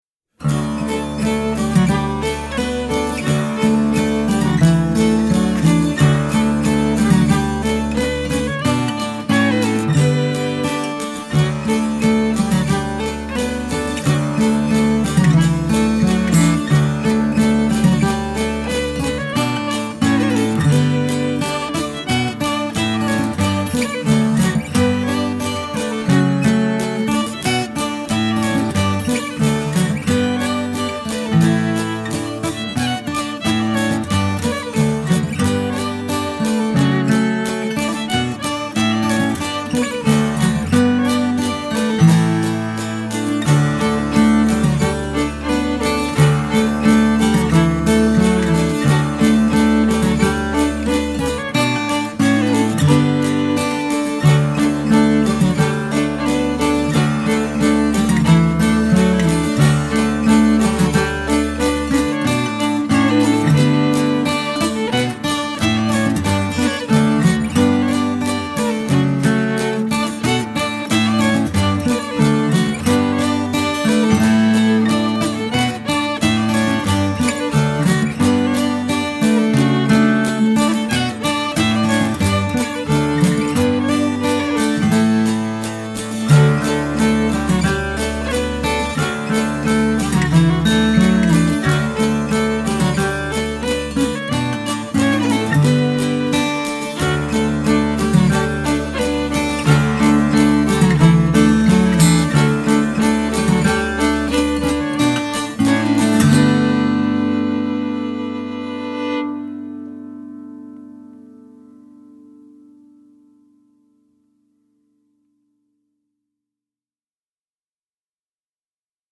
Instrumental Tune